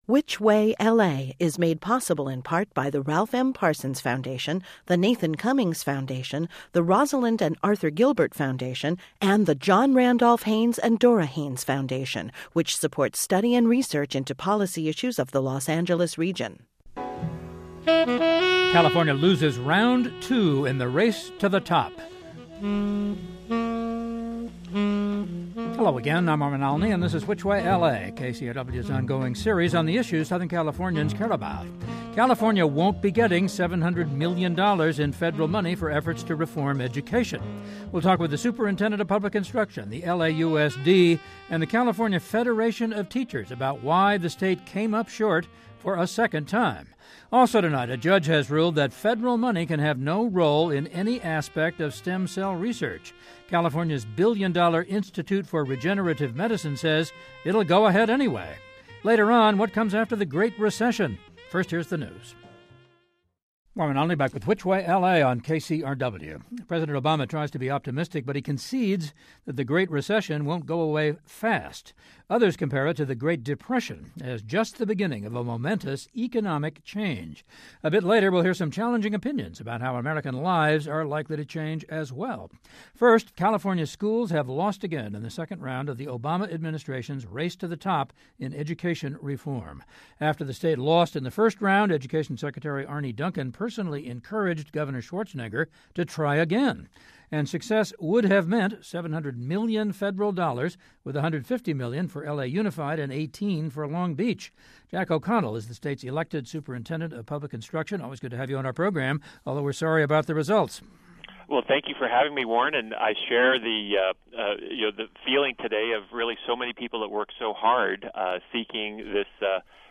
We talk with the Superintendent of Public Instruction, the LAUSD and the California Federation of Teachers about why the State came up short for a second time. Also, a judge has ruled that federal money can have no role in any aspect of stem cell research.